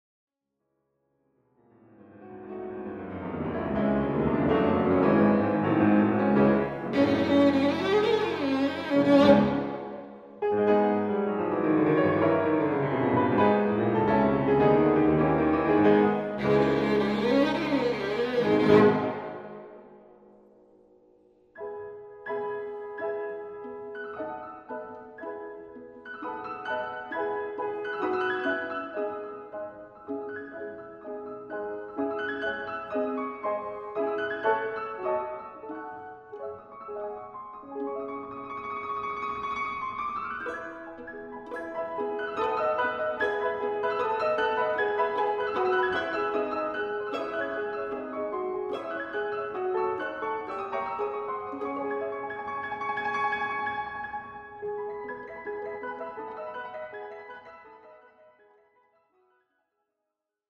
violino
Registrazione: 2 e 3 marzo 2012, Sala Streicher, Salisburgo.